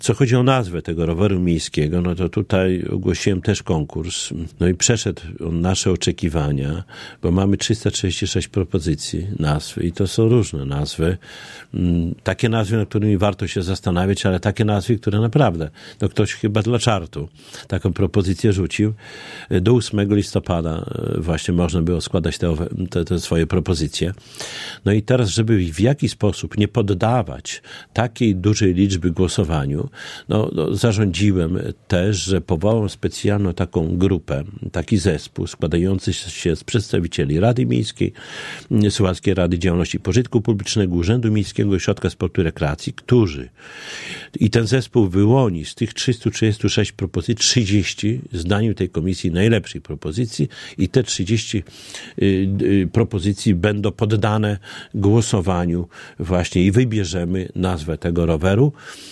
– Teraz mieszkańcy mogą opowiedzieć się, jaka nazwa przypadła im do gustu – mówi Czesław Renkiewicz, prezydent Suwałk.